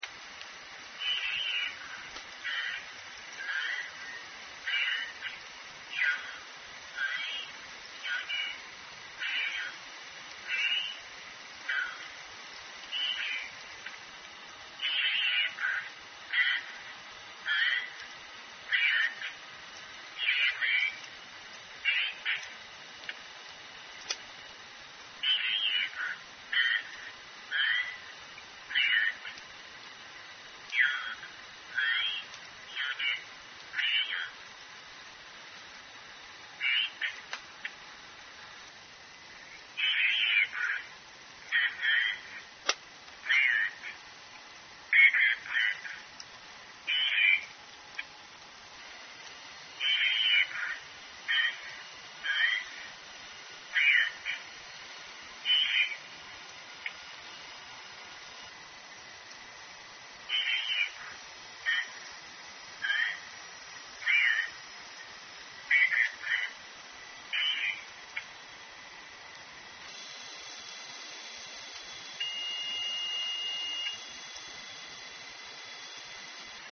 there was a 1.7-second tonal sonar ping at 2.9 kHz.Â  At right is a screen grab from Audacity that shows the ping and the preceding word sequence.Â  Here is a concatenation of the sequences and the ping:
090407_1910clips-lk_voice-sonar